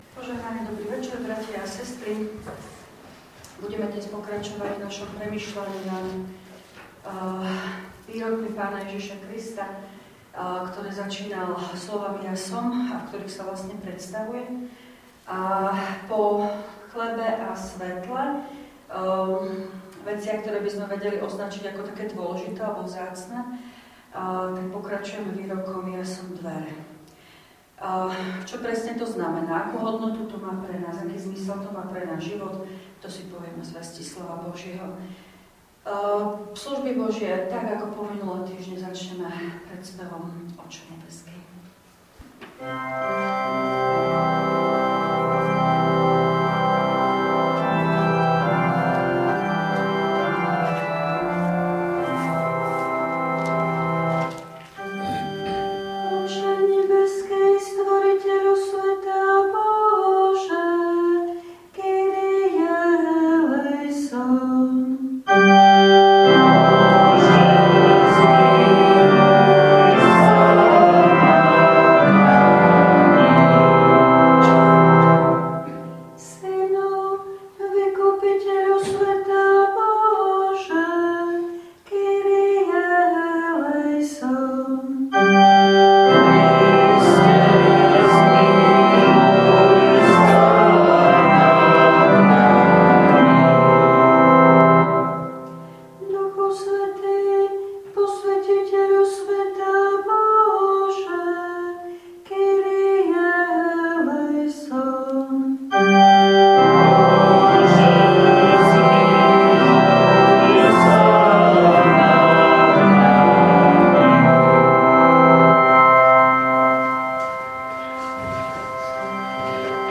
Pôstne večerné služby Božie_2_4_2025
V nasledovnom článku si môžete vypočuť zvukový záznam z večerných pôstnych služieb Božích_2_4_2025.